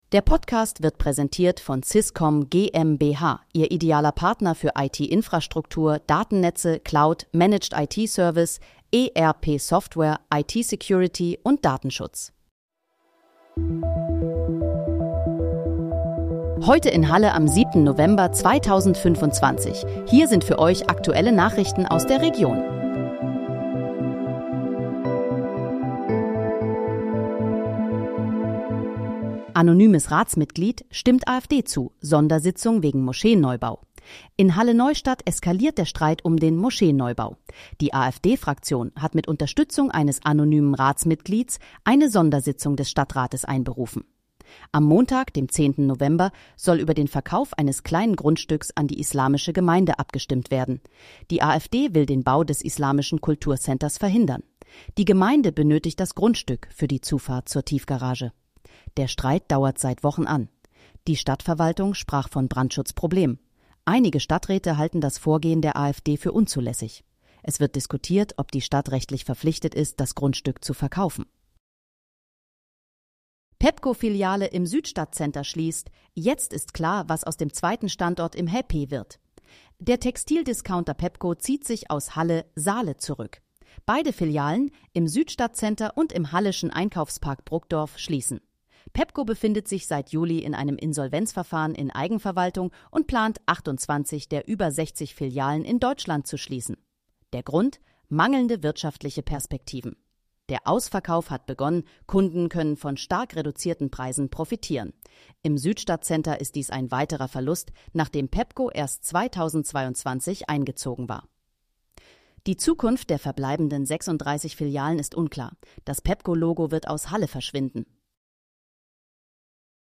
Heute in, Halle: Aktuelle Nachrichten vom 07.11.2025, erstellt mit KI-Unterstützung
Nachrichten